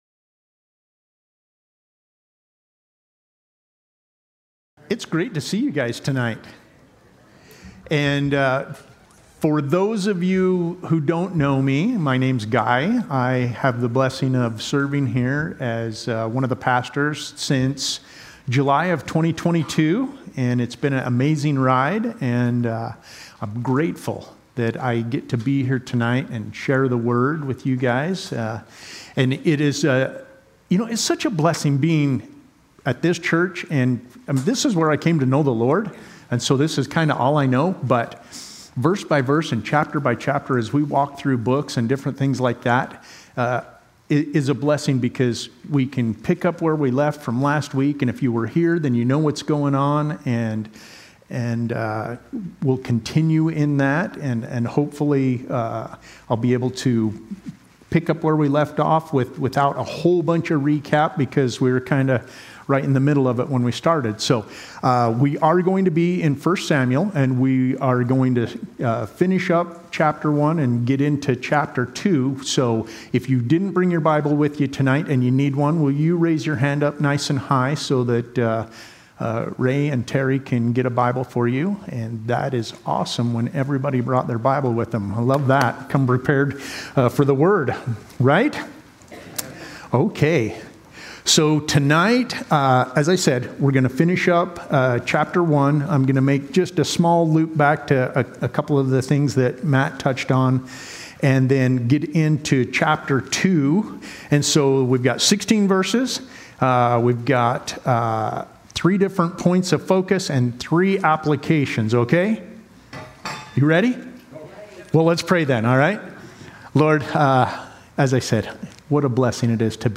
Calvary Chapel Saint George - Sermon Archive